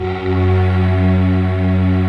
Index of /90_sSampleCDs/Optical Media International - Sonic Images Library/SI1_Breath Choir/SI1_Soft Breath